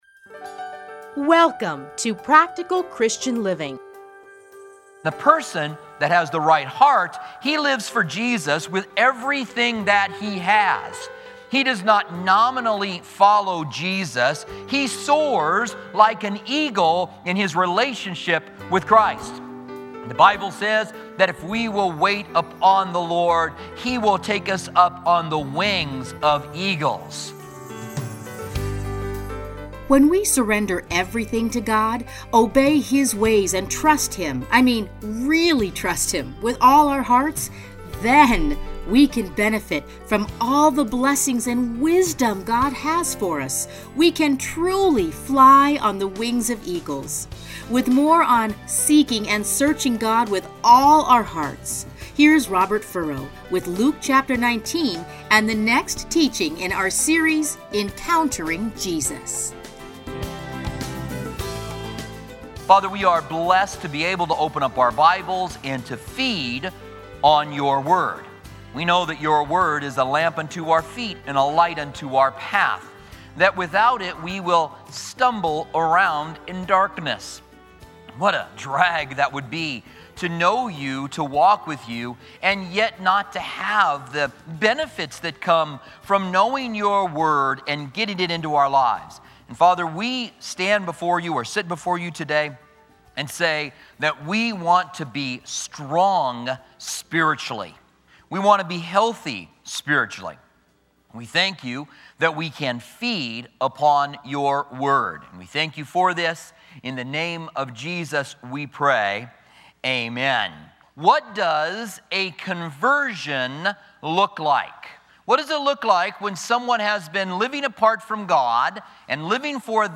teachings are edited into 30-minute radio programs titled Practical Christian Living